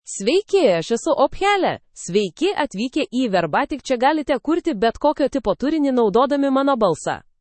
OpheliaFemale Lithuanian AI voice
Ophelia is a female AI voice for Lithuanian (Lithuania).
Voice sample
Listen to Ophelia's female Lithuanian voice.
Female